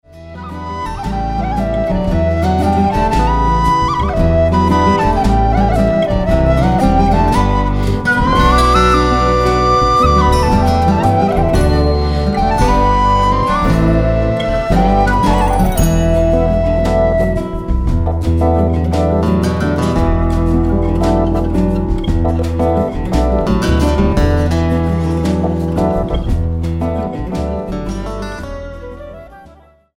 Schlagzeug
Perkussion